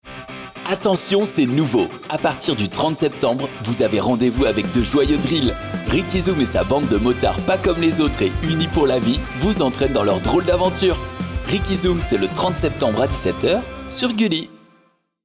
25 - 50 ans - Baryton-basse